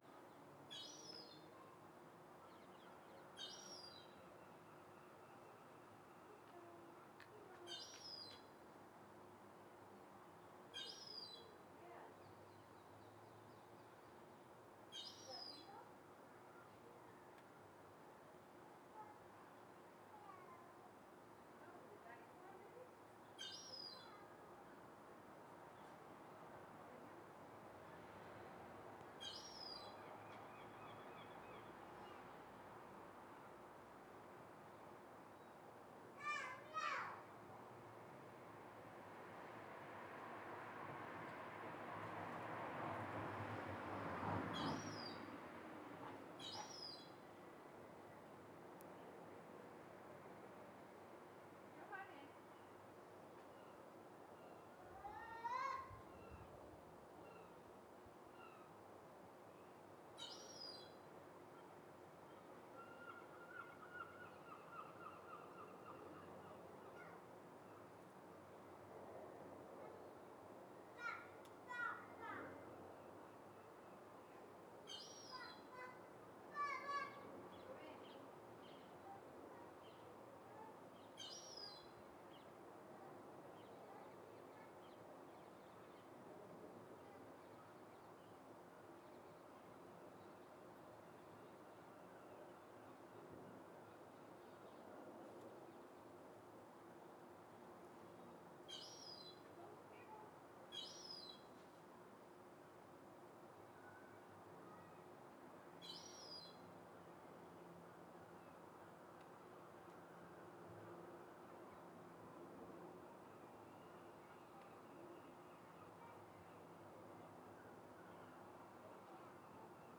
Pretty cold outside, but after 1 week cooped up inside the house due to illness I allowed myself some time just sitting in the garden and looking at things.